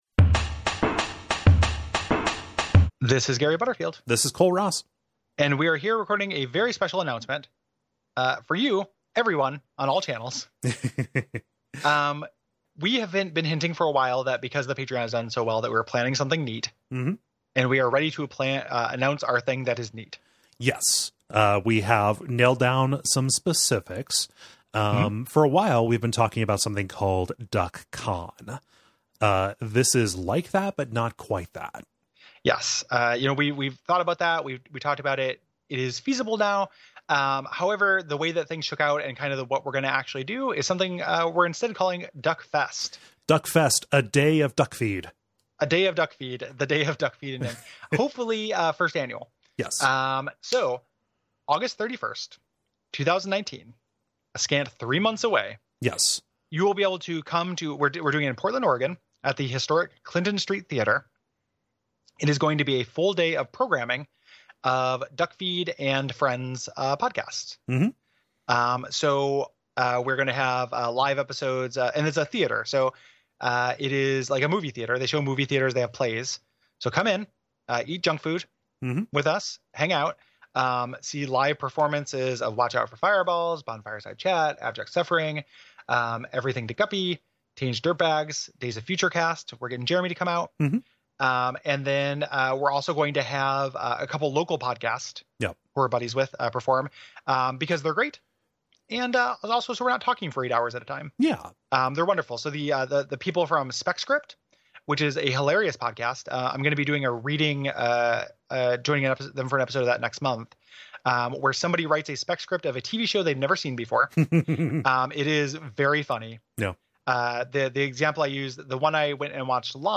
1 [PREVIEW] Halloween Reading: "The Yellow Sign" by Robert W. Chambers